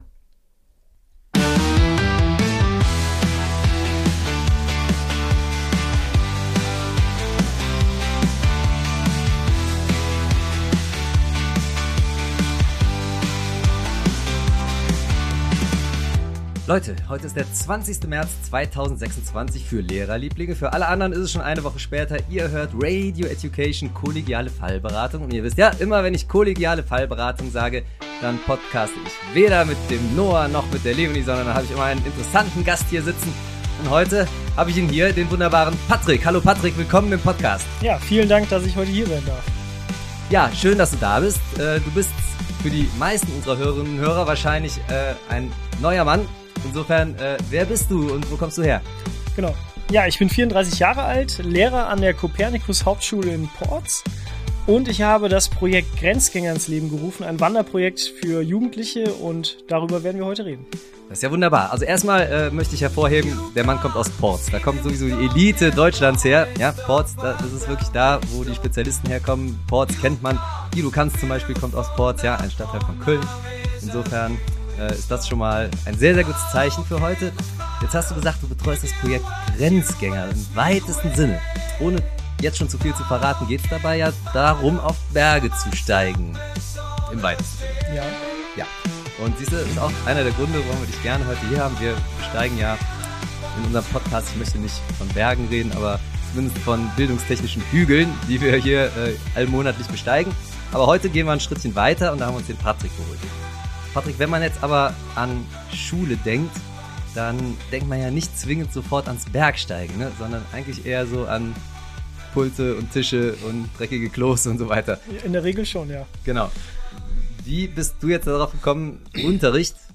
Es geht um echte Geschichten, ungewöhnliche Perspektiven und darum, wie Lernen auch außerhalb klassischer Schulstrukturen stattfinden kann. Ein Gespräch, das inspiriert, zum Nachdenken anregt – und zeigt, dass Bildung manchmal genau dort beginnt, wo sie aufhört, selbstverständlich zu sein.